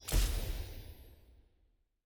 sfx-button-claim-click.ogg